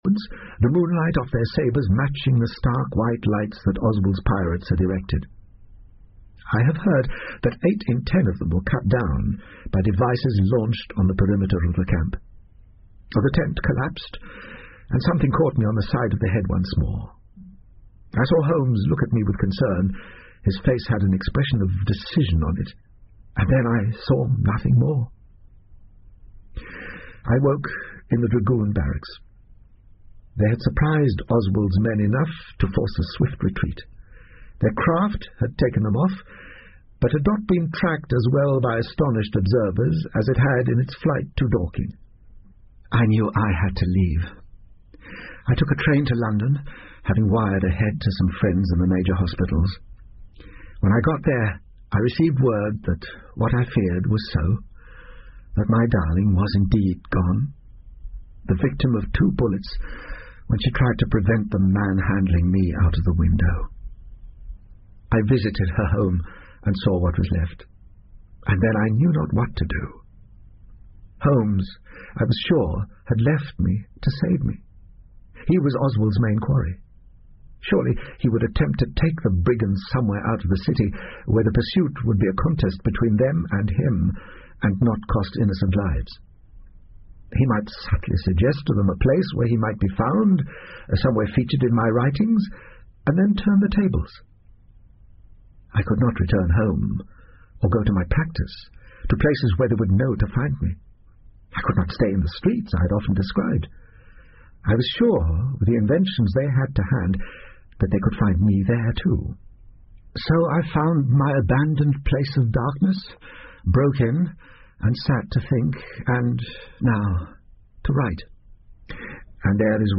福尔摩斯广播剧 Cult-The Deer Stalker 6 听力文件下载—在线英语听力室